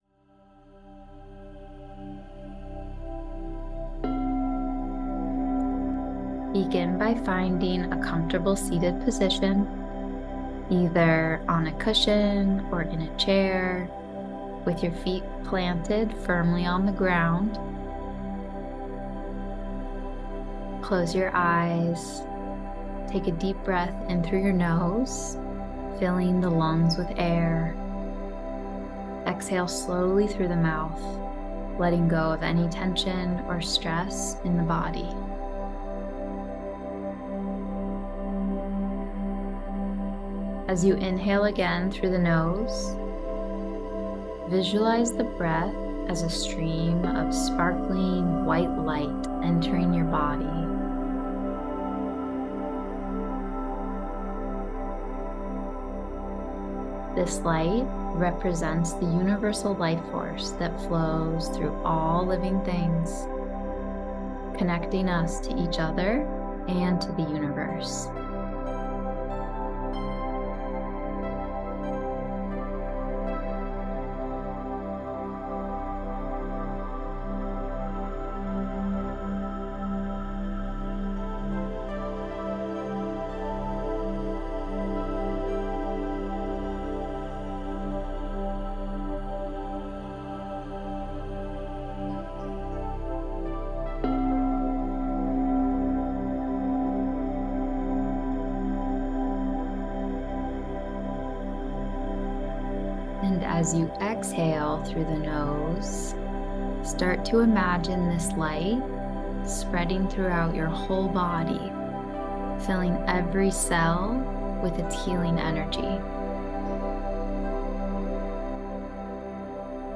Breathwork with Guided Meditation